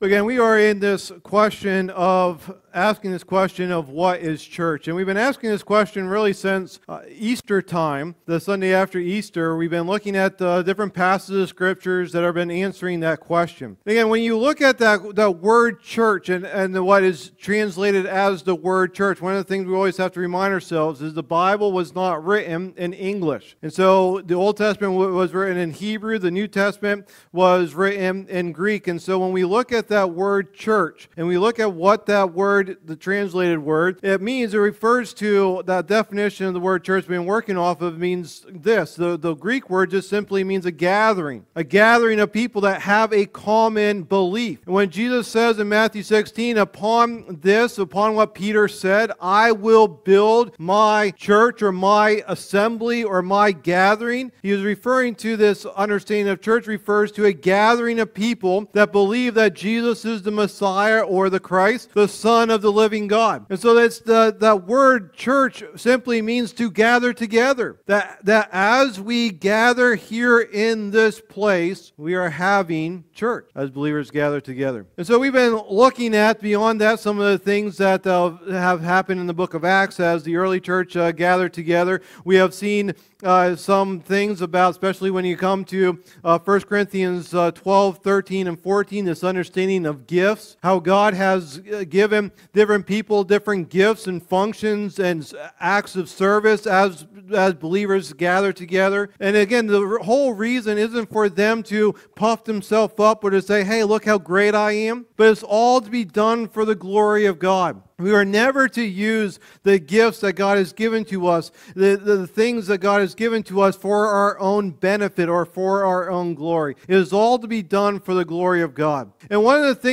Message #12 in the "What is Church?" teaching series